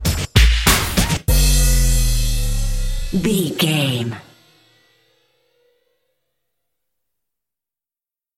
Ionian/Major
drum machine
synthesiser
funky
aggressive
driving